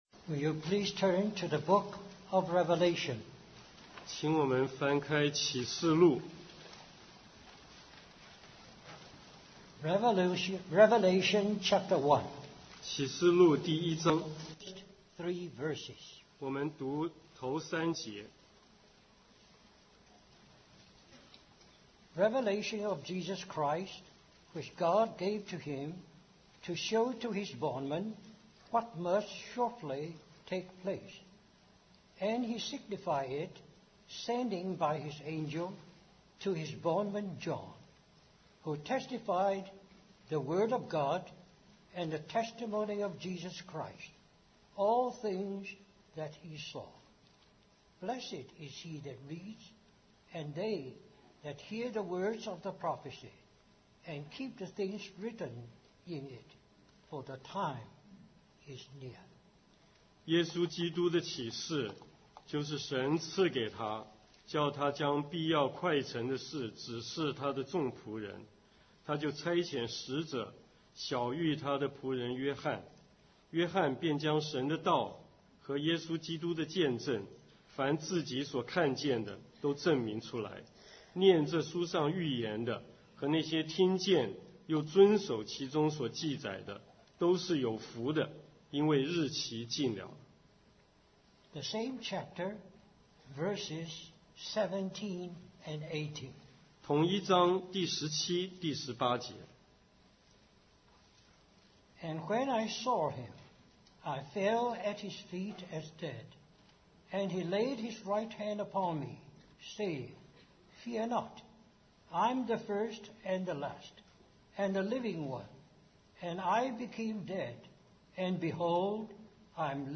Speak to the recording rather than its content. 2009 West Coast Christian Conference Stream or download mp3 Topics Purpose Scriptures Referenced Revelation 19:10 10 And I fell at his feet to worship him.